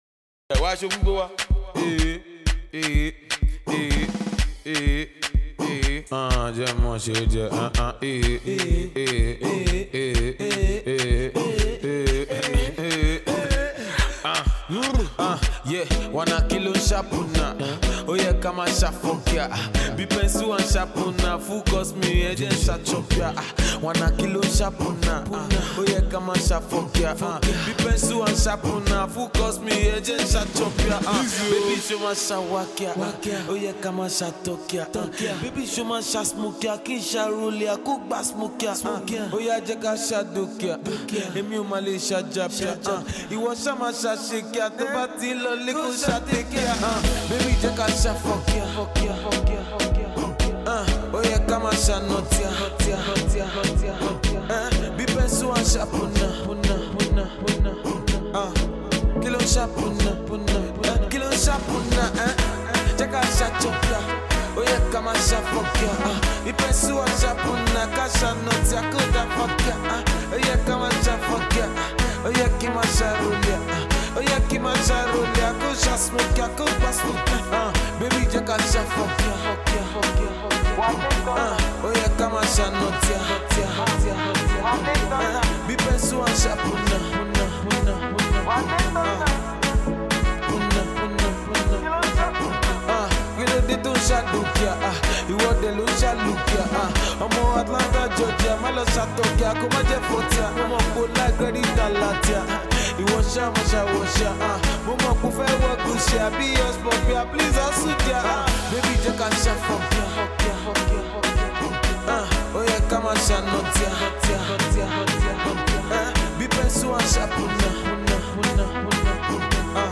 melodious and upbeat tune